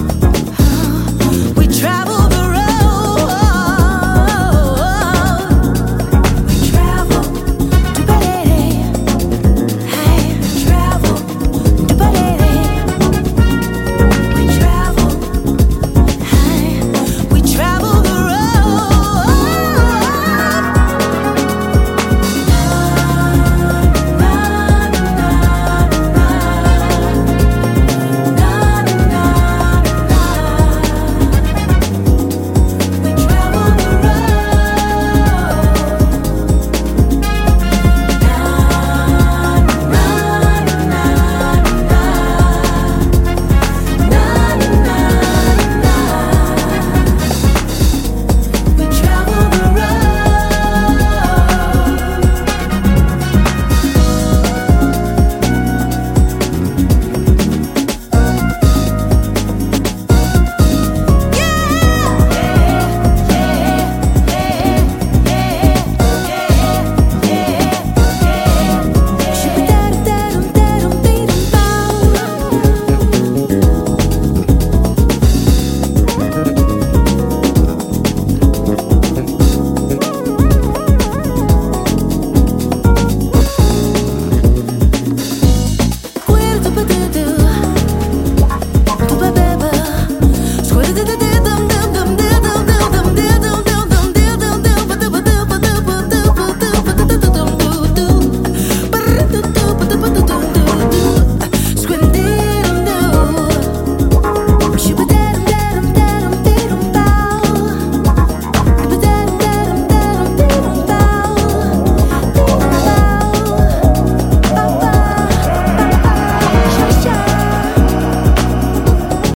Soulful bliss, strong vocals
Broken beat